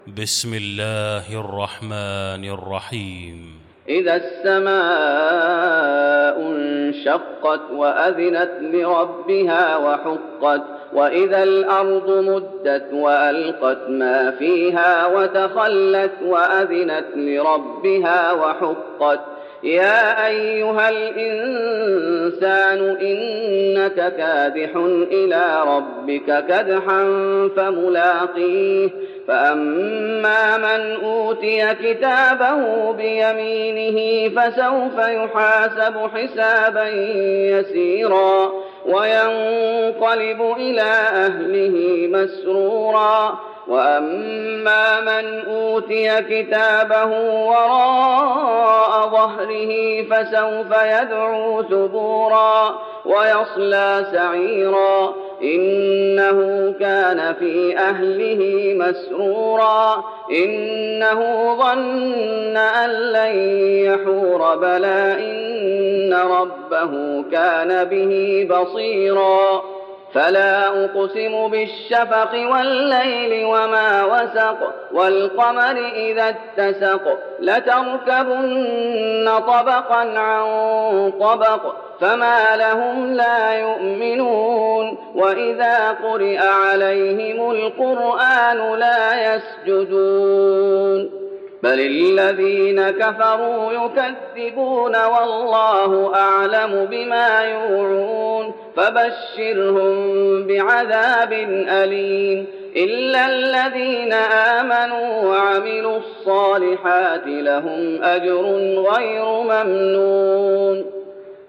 المكان: المسجد النبوي الانشقاق